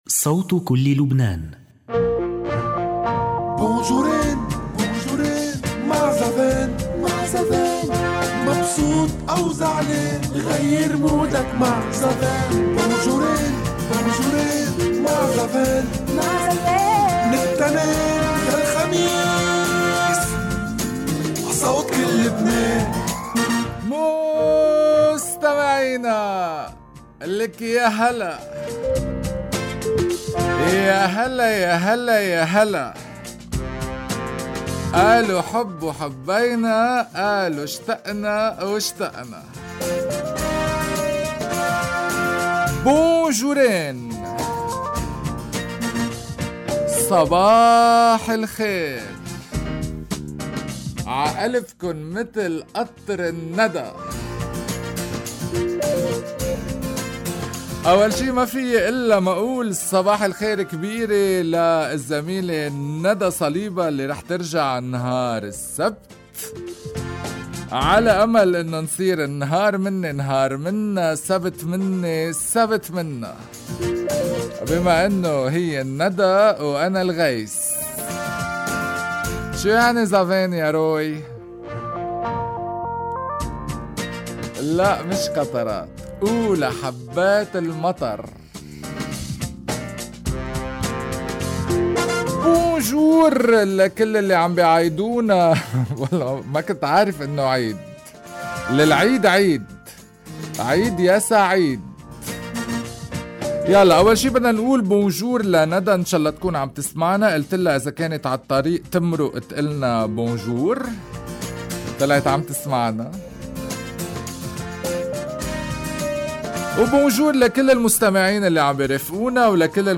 - حلقة مفتوحة لإتصالات المستمعين.